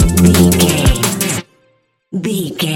Ionian/Major
C♭
electronic
dance
techno
trance
synths
synthwave
instrumentals